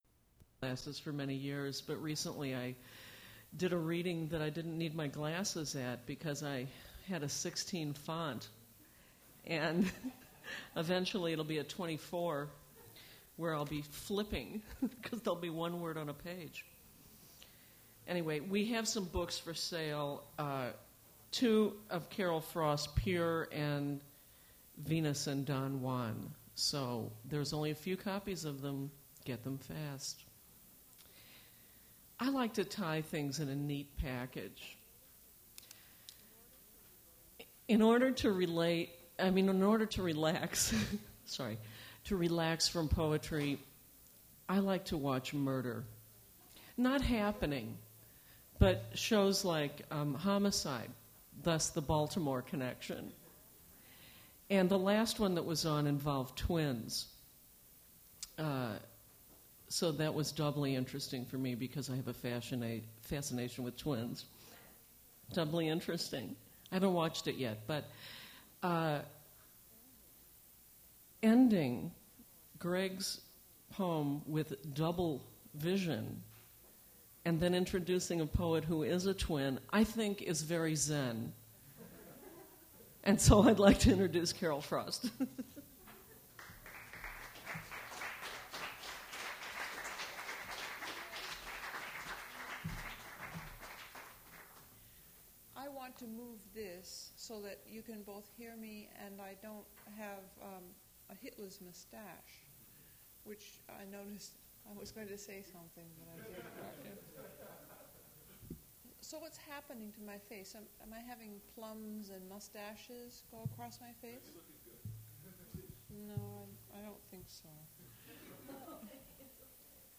Poetry reading featuring Carol Frost
Attributes Attribute Name Values Description Carol Frost reading her poetry at Duff's Restaurant for the River Styx at Duff's Poetry Series.
mp3 edited access file was created from unedited access file which was sourced from preservation WAV file that was generated from original audio cassette.
recording starts mid-introduction